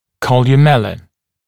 [ˌkɔljuˈmelə][ˌколйуˈмэлэ]колумелла (перегородка преддверия носа)